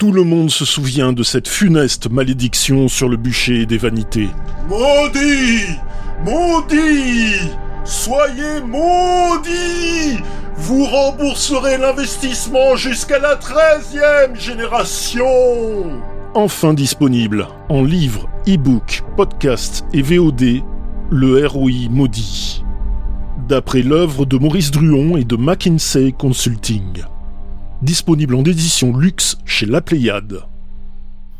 Fausse publicité : Le R.O.I. Maudit